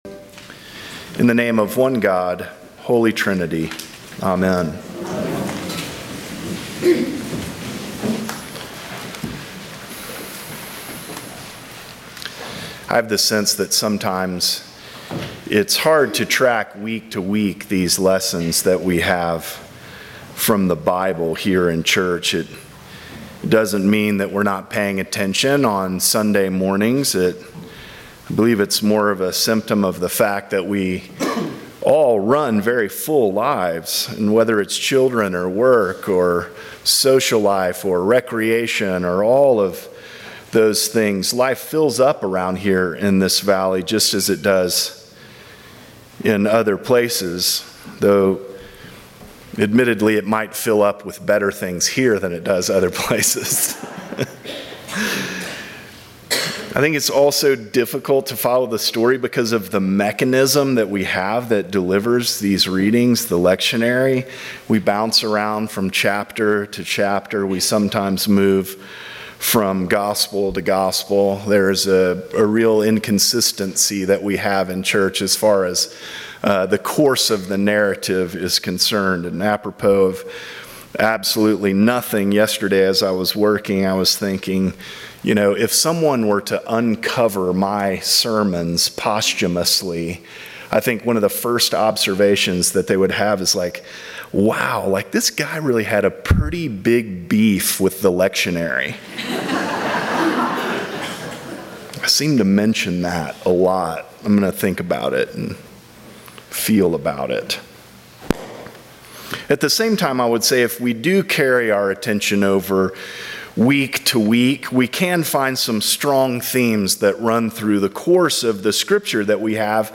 Sermons from St. John's Episcopal Church Mary & Martha